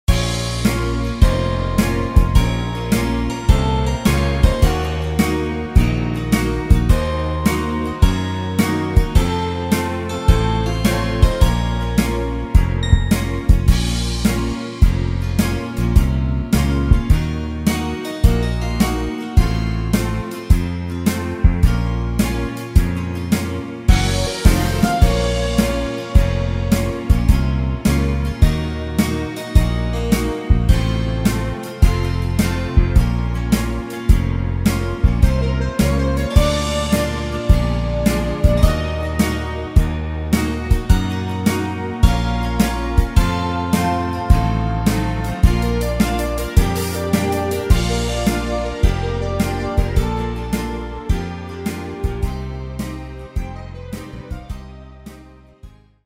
sans aucun applaudissement